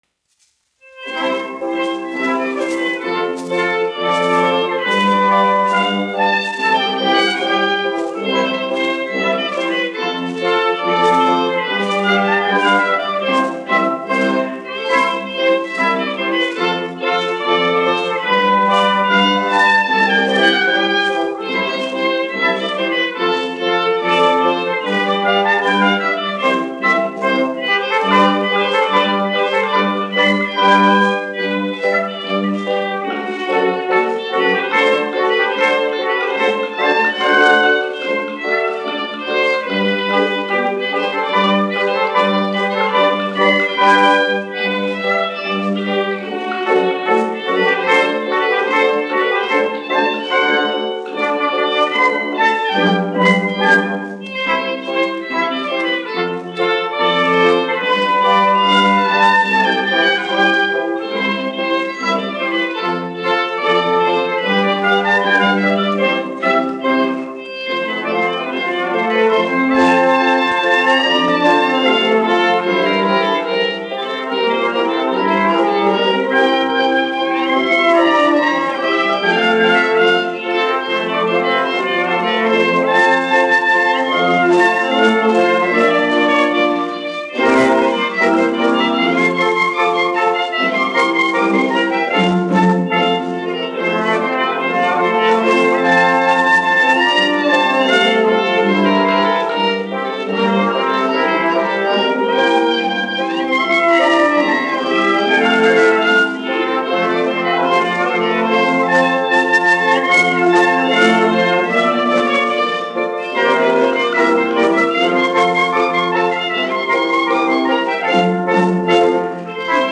Reinlenders
Marcella (mūzikas grupa), izpildītājs
1 skpl. : analogs, 78 apgr/min, mono ; 25 cm
Sarīkojumu dejas